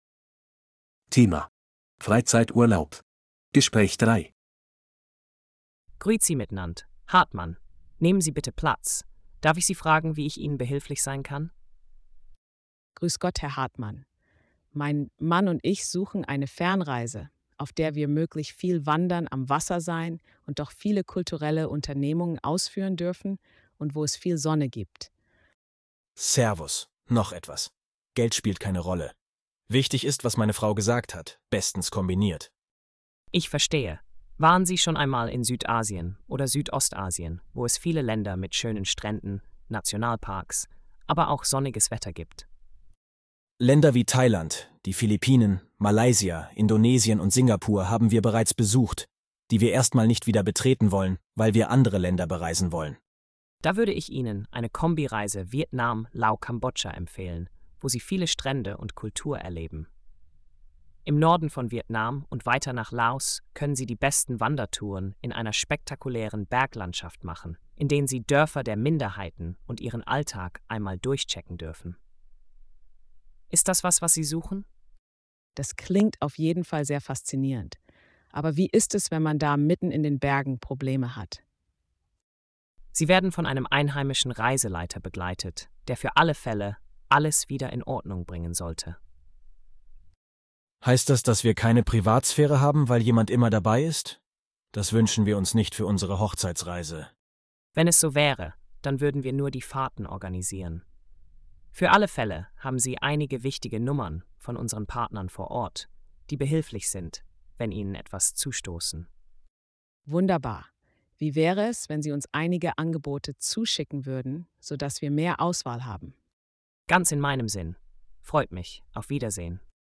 Audio text conversation 3:
A2-Kostenlose-R-Uebungssatz-7-Freizeit-Urlaub-Gespraech-3.mp3